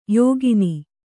♪ yōgini